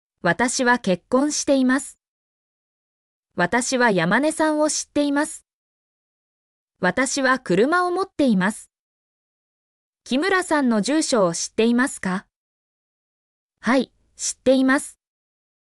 mp3-output-ttsfreedotcom-34_rXeze3fO.mp3